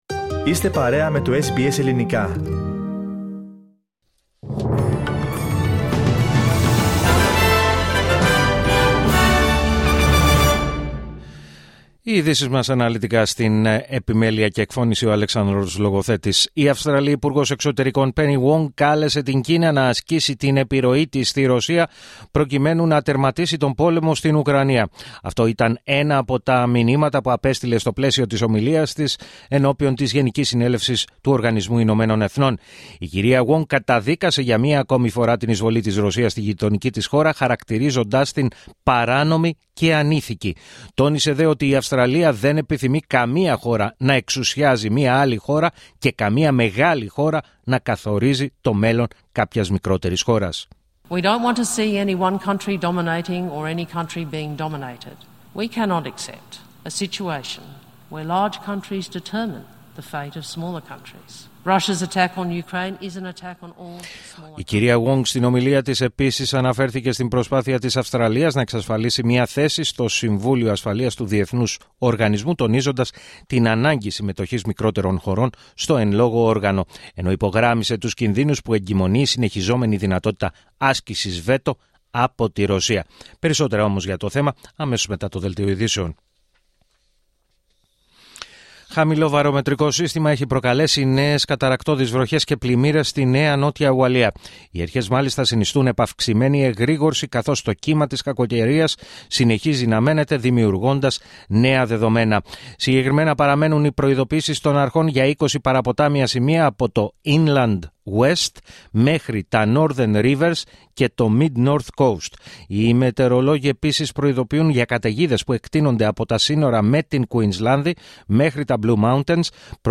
Το αναλυτικό δελτίο ειδήσεων του Ελληνικού Προγράμματος της ραδιοφωνίας SBS, στις 4 μμ.